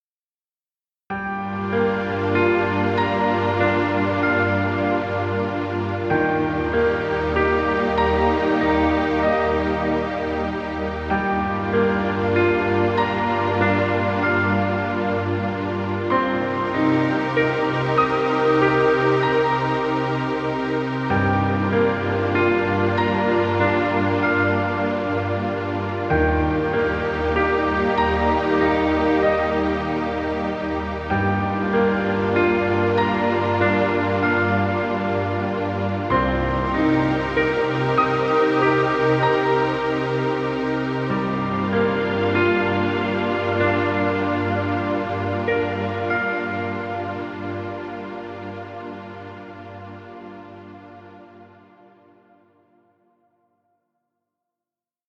Relaxing piano music.